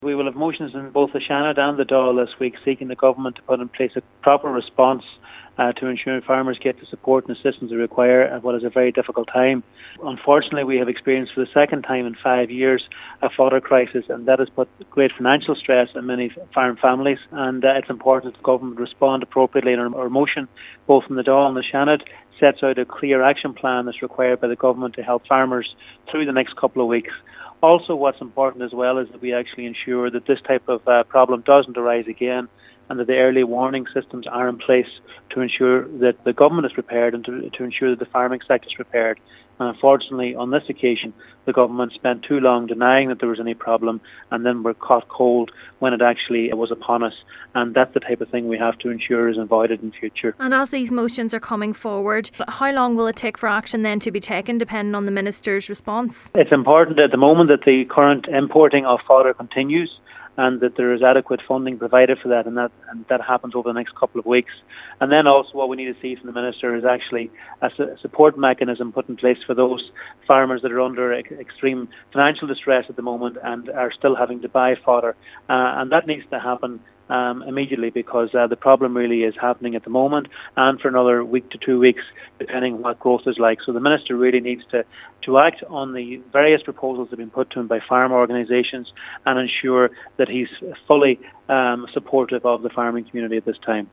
He says while vital action must be taken now to tackle the crisis, it is important to protect against the recourring of the situation: